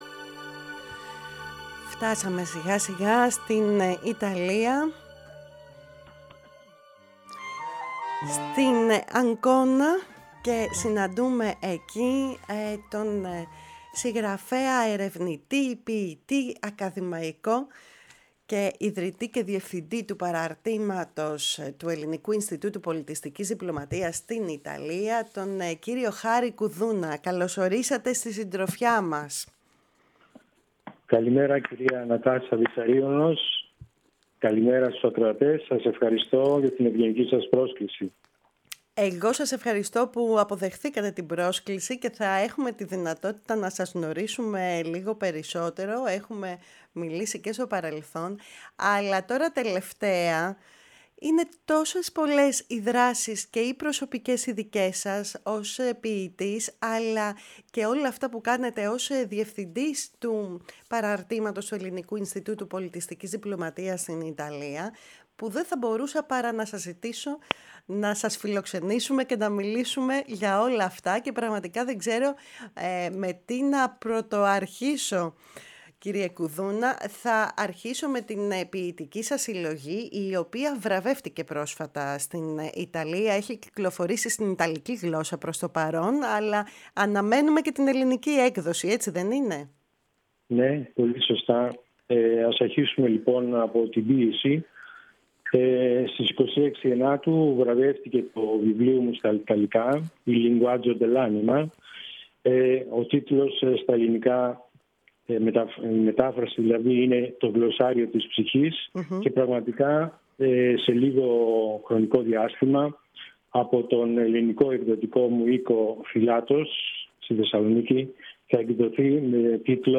Στη «Φωνή της Ελλάδας» και συγκεκριμένα στην εκπομπή «Κουβέντες μακρινές»